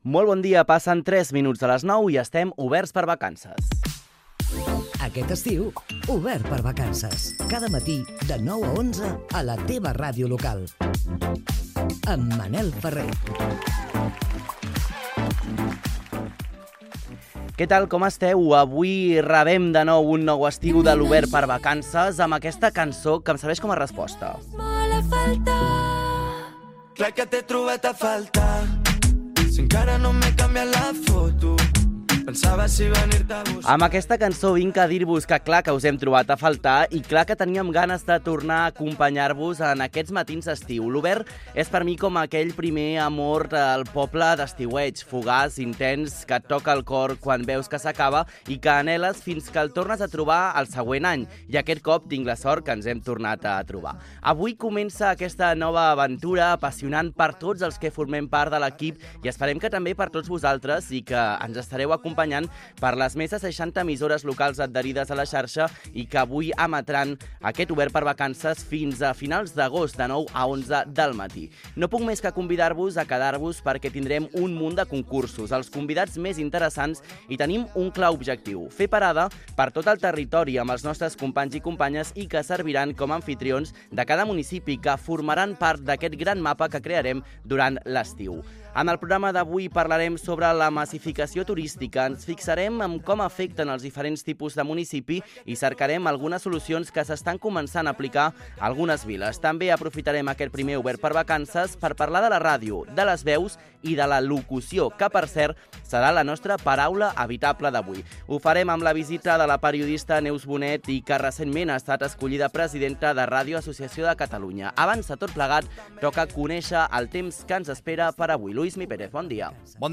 Inici del primer programa, Indicatiu i sumari. Informació del temps. Connexió amb Altafulla Ràdio per donar pistes d'un joc. Indicatiu del programa.
Entreteniment